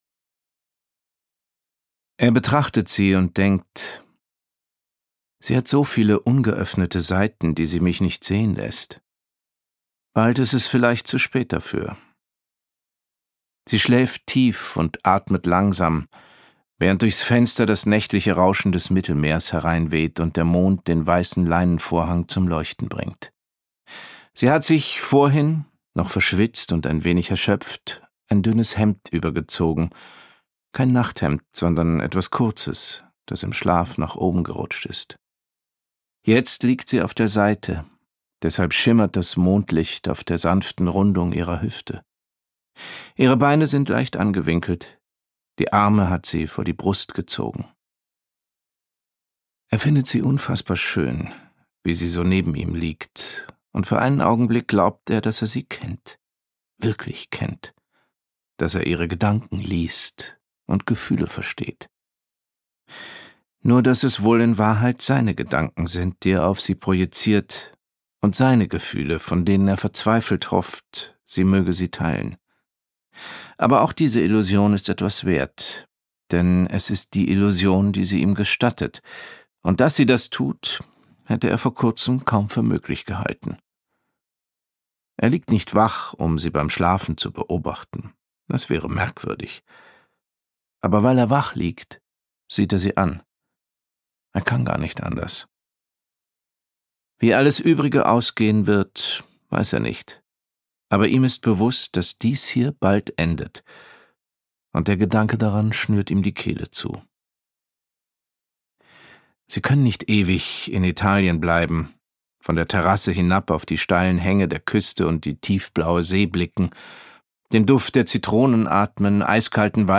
Gekürzt Autorisierte, d.h. von Autor:innen und / oder Verlagen freigegebene, bearbeitete Fassung.
Das Antiquariat am alten Friedhof Gelesen von: Johann von Bülow, Luise Helm